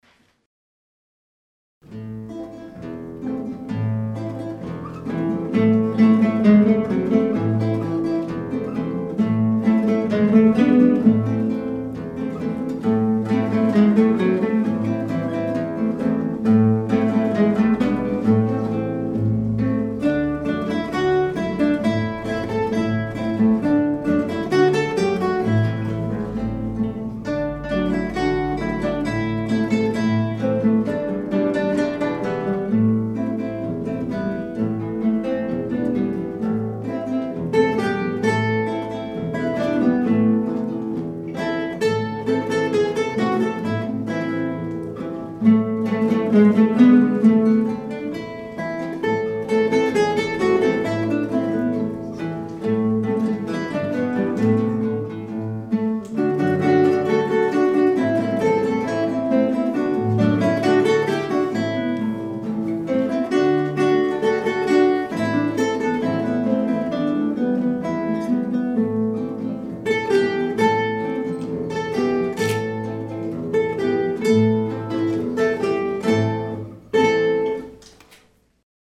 • Kytarový orchestr
Hráči používají klasické kytary ve standardním ladění, 1/8 kytary se sopránovými oktávovými strunami a klasickou basovou kytaru se strunami laděnými o oktávu níže, popř. sólovou elektrickou kytaru (u úprav rockových skladeb). Do některých skladeb bývají také zapojeny perkusní či Orffovy nástroje.